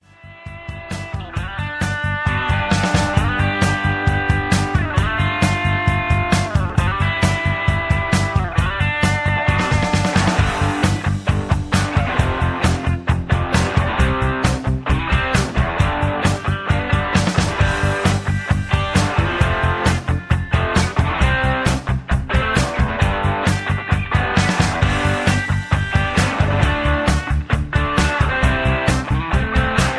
backing tracks
country rock, southern rock